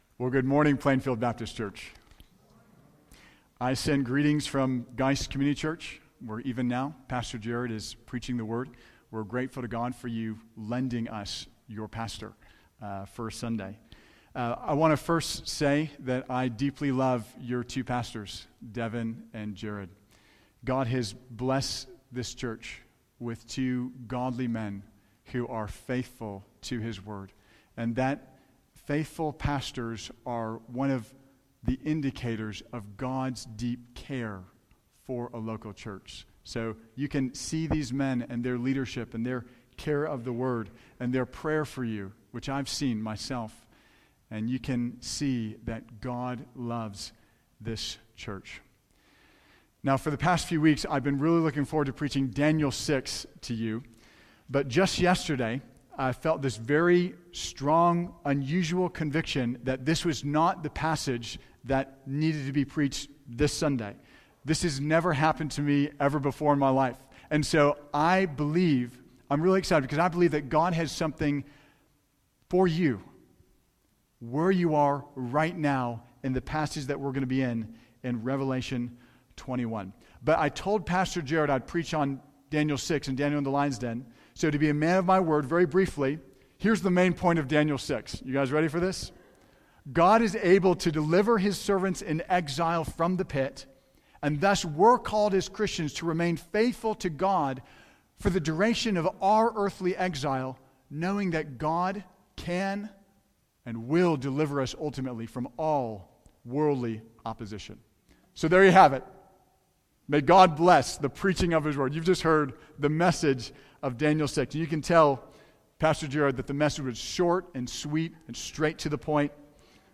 Sermon Audio 2020 February 23